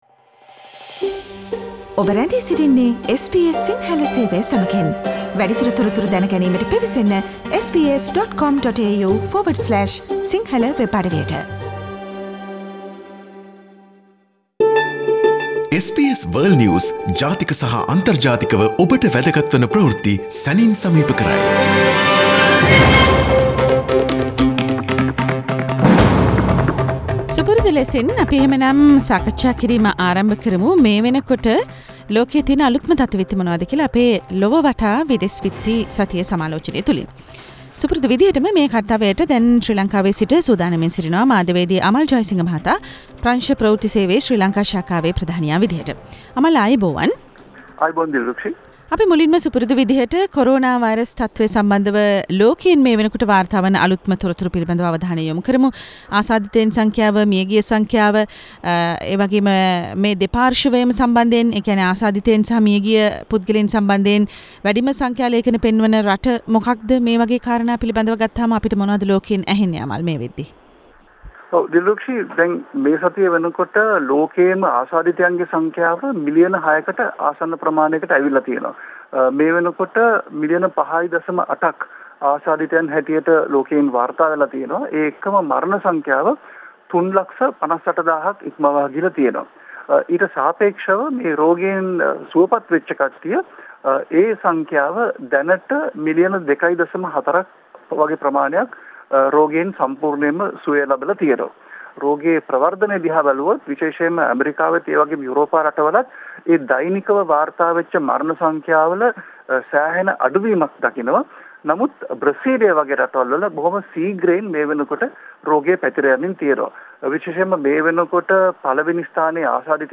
SBS Sinhala weekly world news wrap Source: SBS Sinhala radio